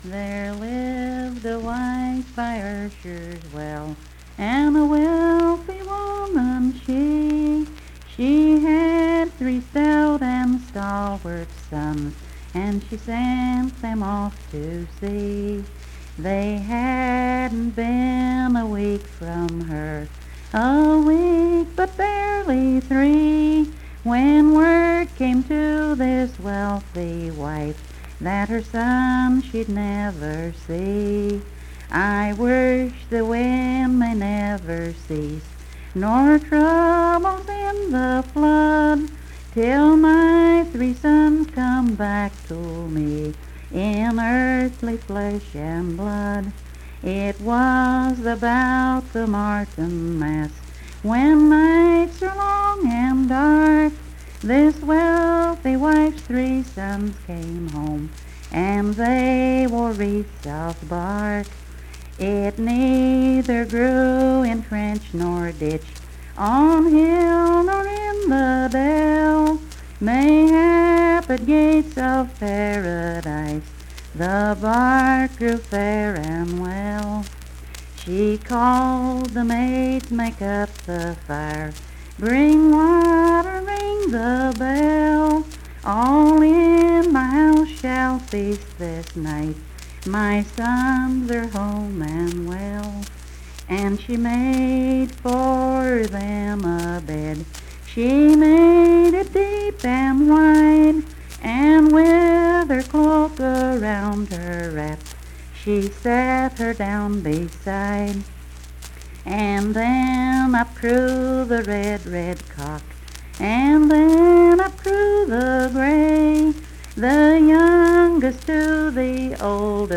Unaccompanied vocal music performance
Verse-refrain 12(4).
Voice (sung)